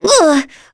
Talisha-Vox_Attack4.wav